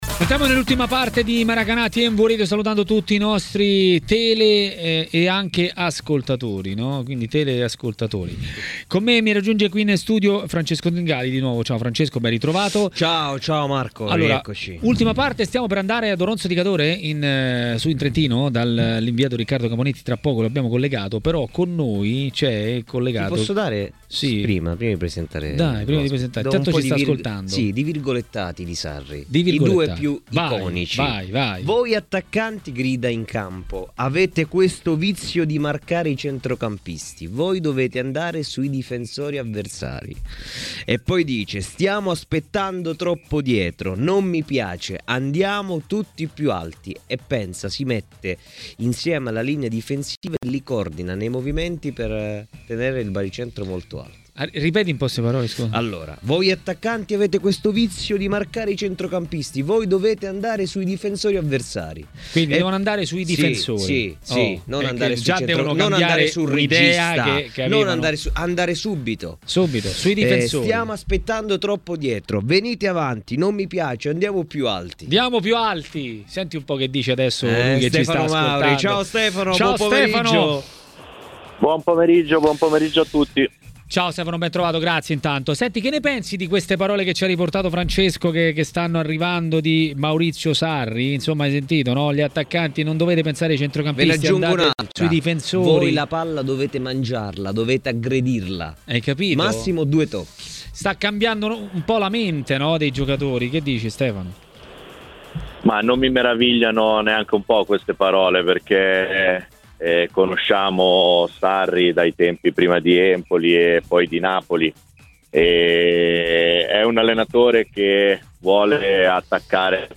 A parlare di Lazio a TMW Radio, durante Maracanà, è tato l'ex centrocampista Stefano Mauri.